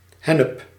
Ääntäminen
Synonyymit cannabis Ääntäminen France: IPA: [ʃɑ̃vʁ] Haettu sana löytyi näillä lähdekielillä: ranska Käännös Ääninäyte Substantiivit 1. hennep {m} Suku: m .